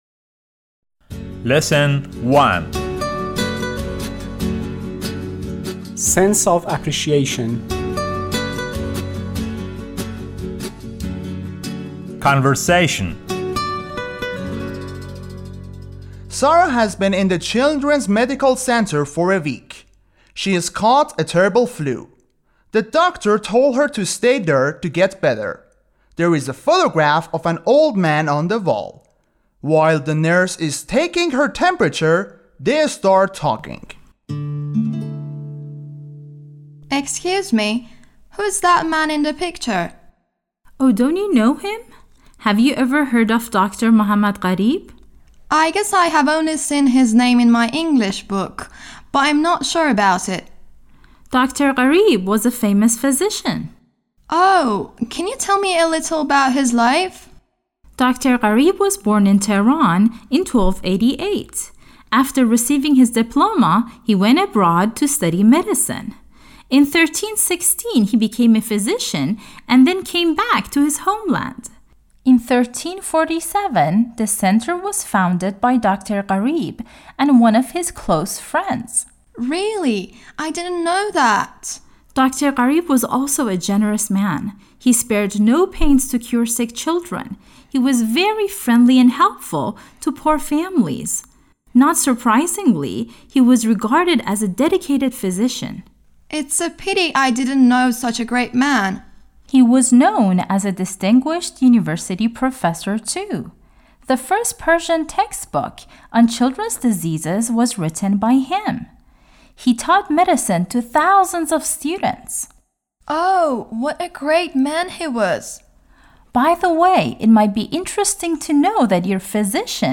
12-L1-Conversation
12-L1-Conversation.mp3